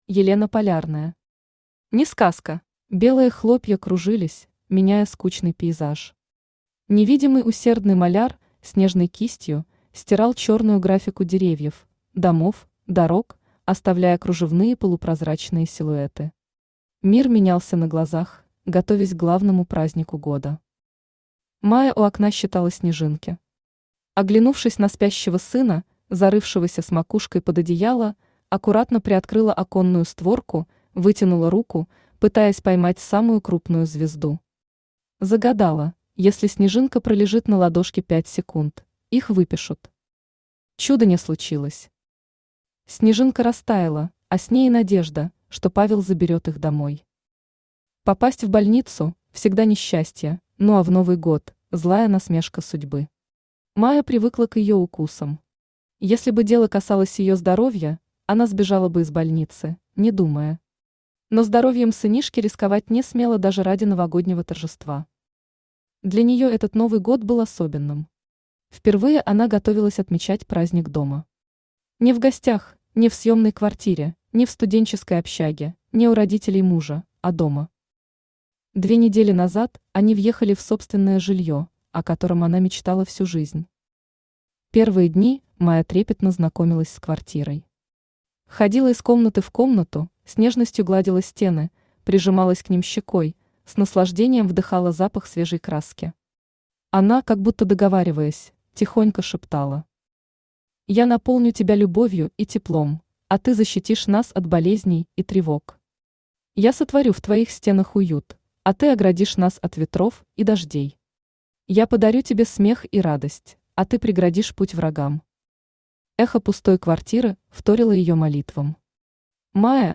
Аудиокнига Несказка | Библиотека аудиокниг
Aудиокнига Несказка Автор Елена Андреевна Полярная Читает аудиокнигу Авточтец ЛитРес.